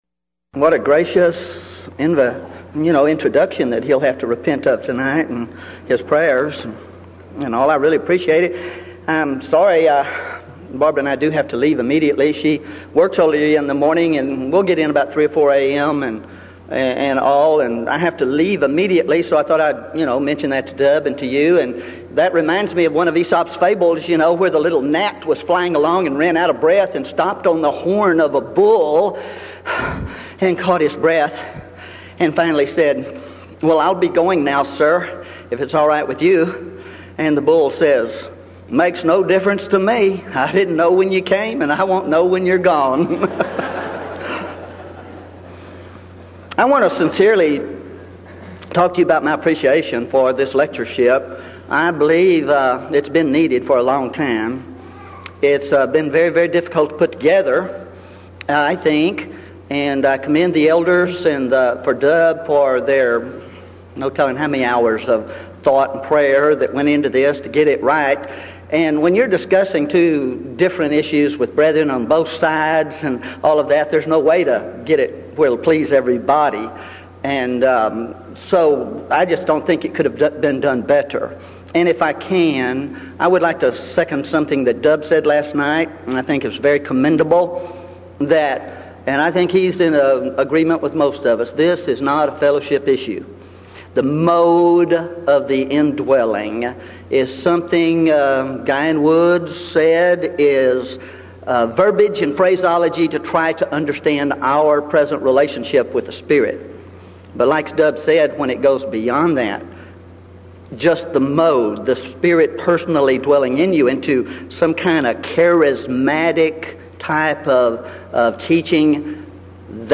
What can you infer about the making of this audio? Event: 1996 Denton Lectures